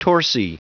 Prononciation du mot torsi en anglais (fichier audio)